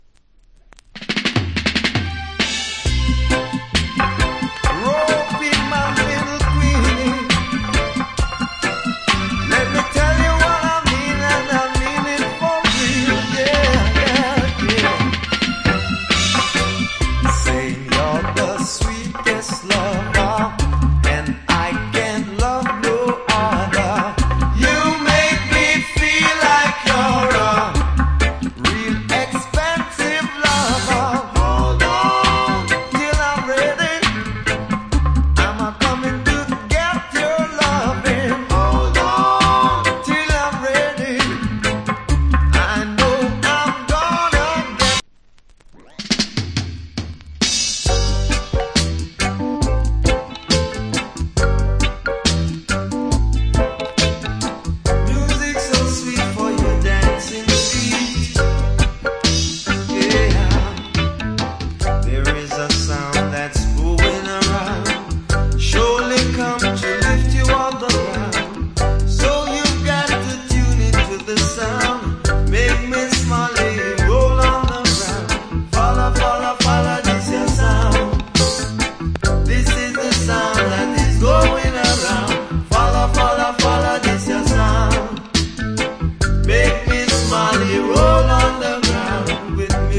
Good Reggae Vocal.